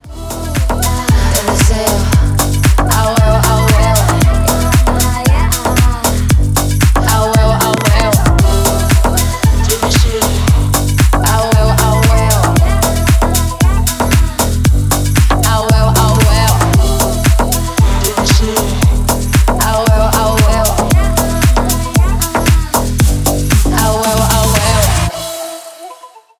Танцевальные
латинские